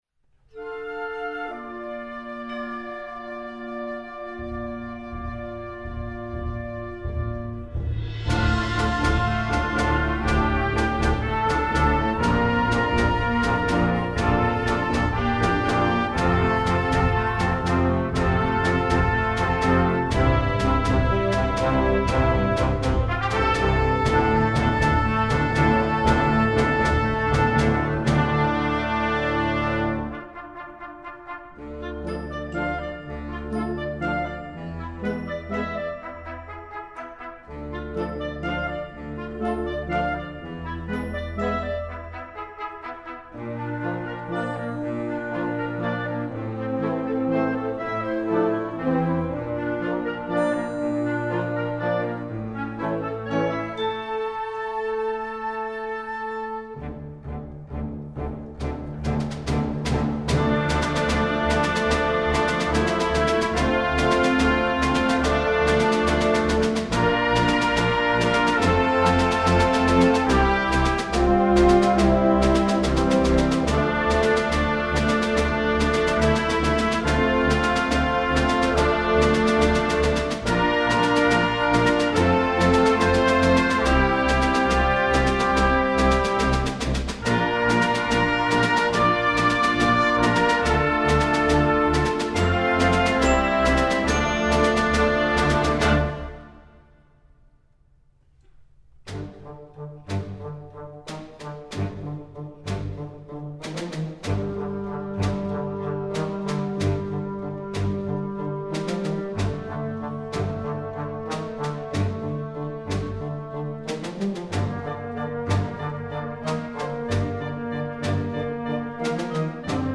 Gattung: Filmmusik
Besetzung: Blasorchester
Dieses actiongeladene Medley